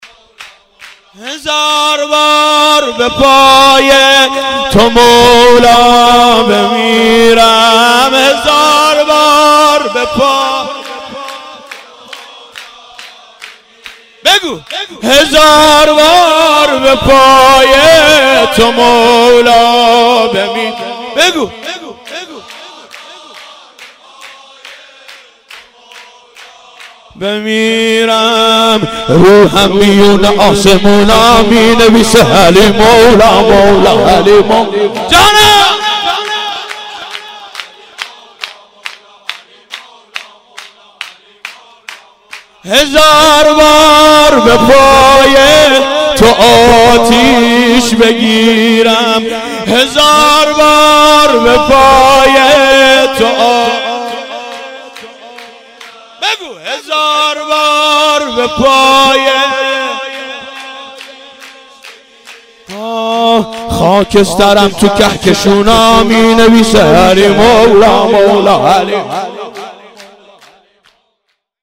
سرود اول